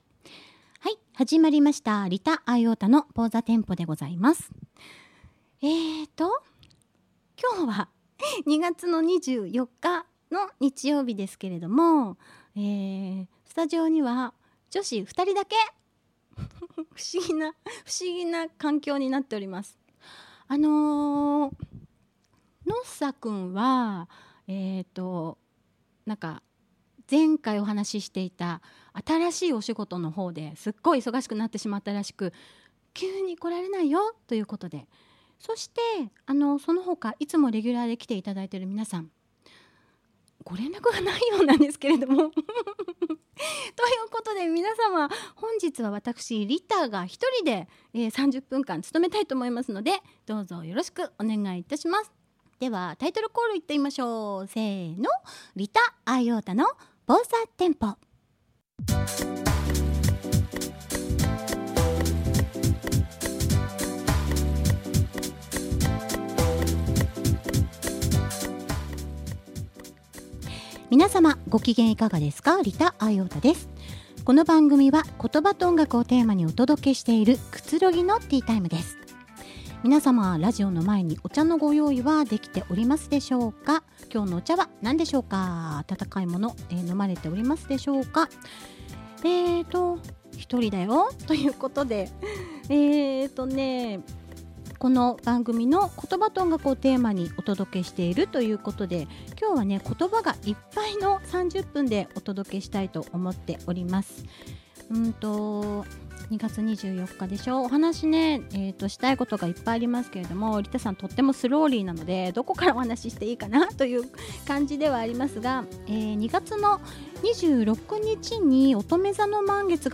ゆる〜く、また〜りとお届け。
オリジナル朗読